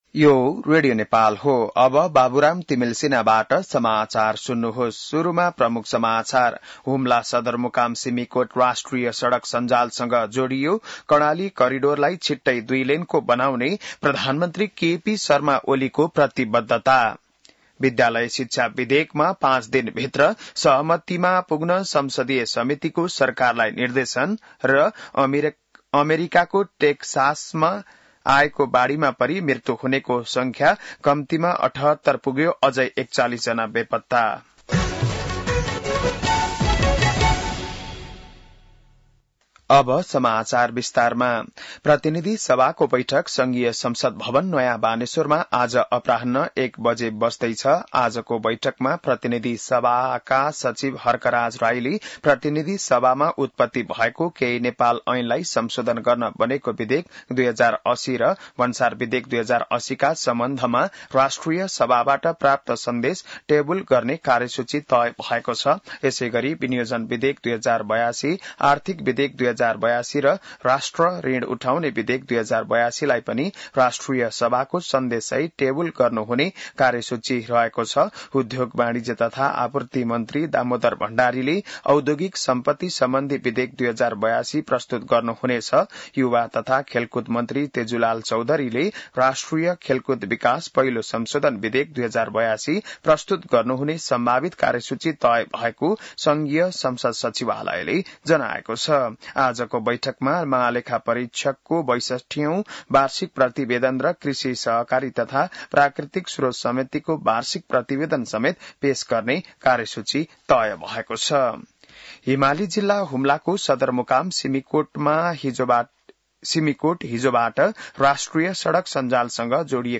बिहान ९ बजेको नेपाली समाचार : २३ असार , २०८२